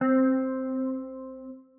Guitar - Cortex.wav